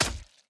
Sword Impact Hit 1.ogg